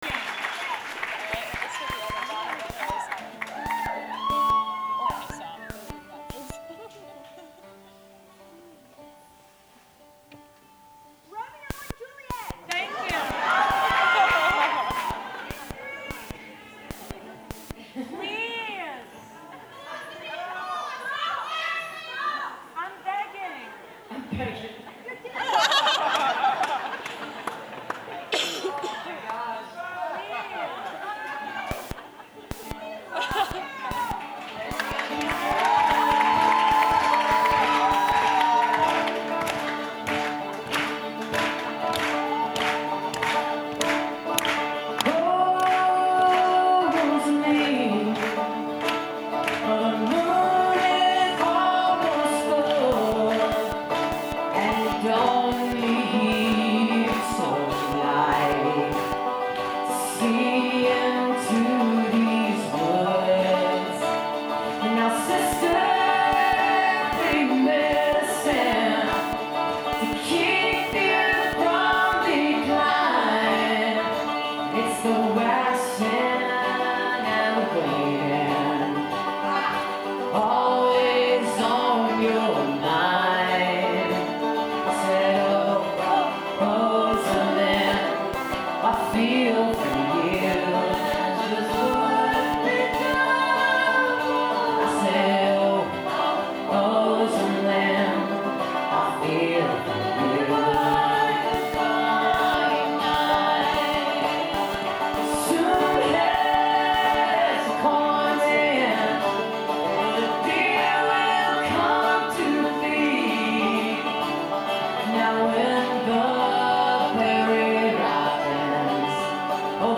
zankel hall - carnegie (acjw) - new york, new york